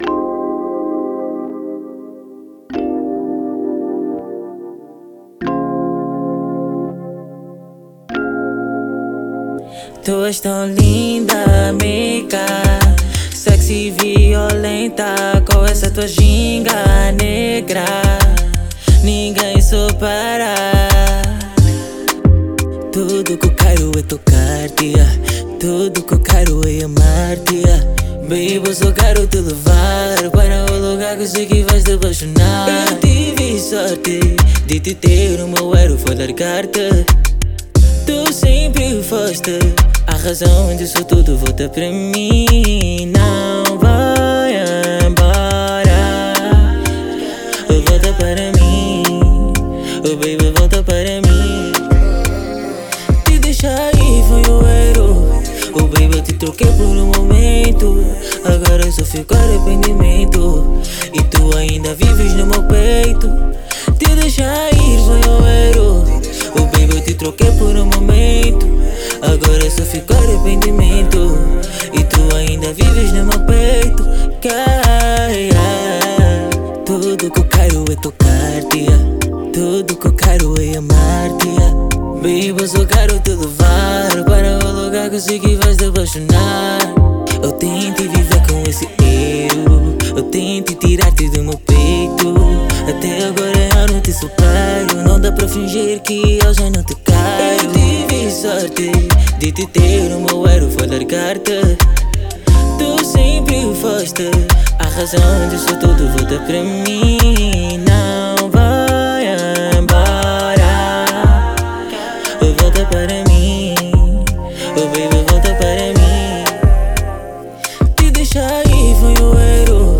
Kizomba
Com uma sonoridade envolvente de Kizomba